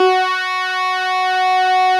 Added synth instrument
snes_synth_054.wav